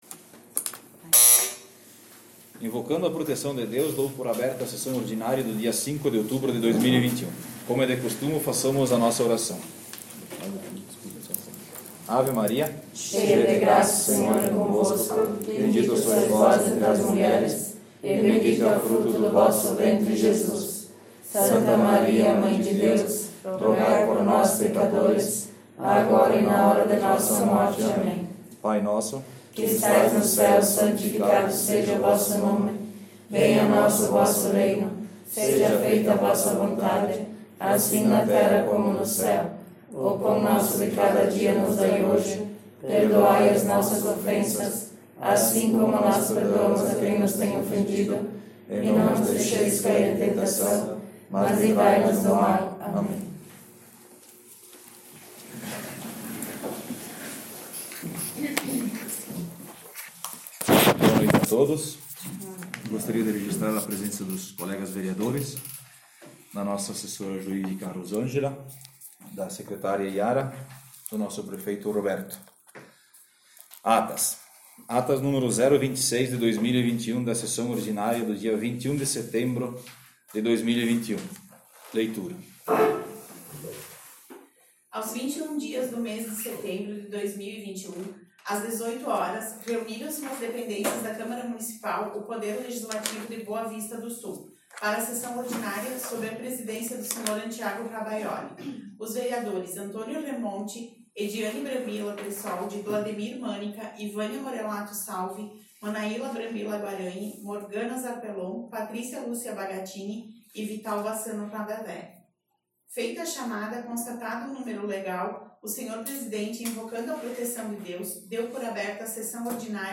Sessão Ordinária 05 de outubro